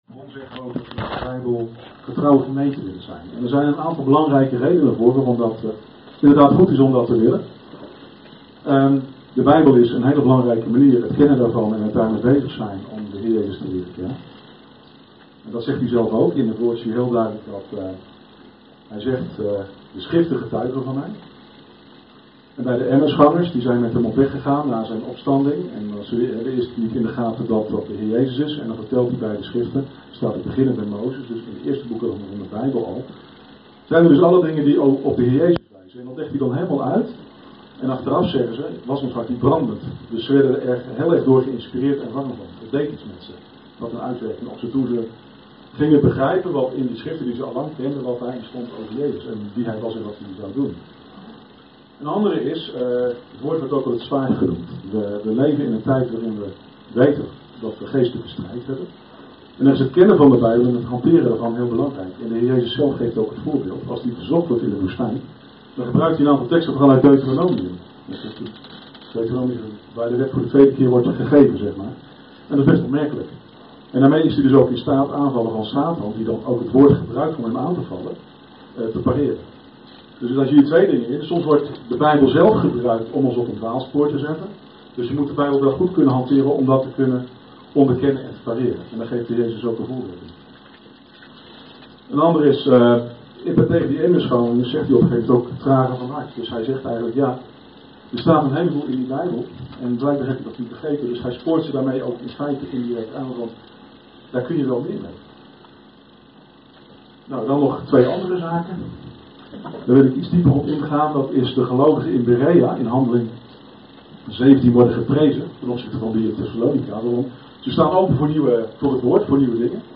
Toespraak van 25 september: intro op de Bijbelse profetie, Israël en de Gemeente - De Bron Eindhoven